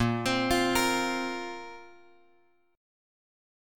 A#sus2 chord {x x 8 5 6 6} chord